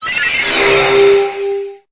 Cri de Cosmovum dans Pokémon Épée et Bouclier.